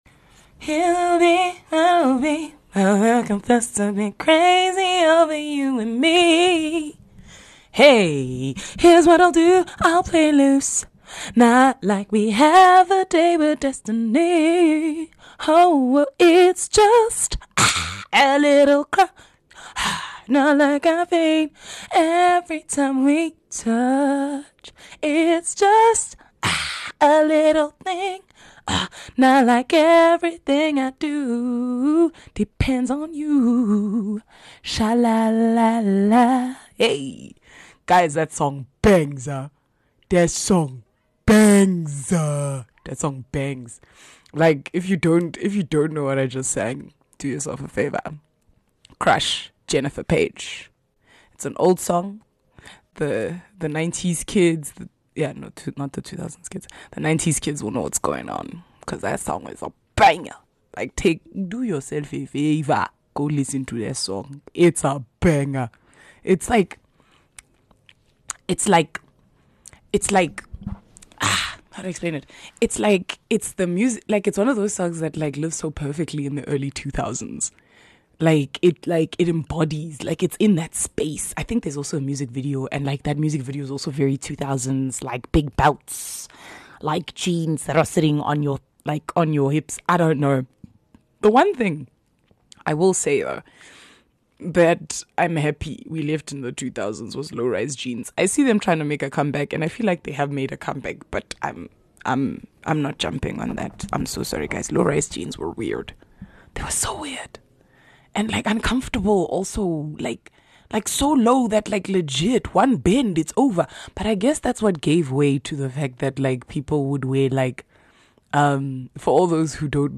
this one is because i am going through some real reflection and why not deal with a mic and the internet listening, also i do sing to start the episode...sorry not sorry.